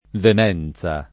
vemenza [ vem $ n Z a ]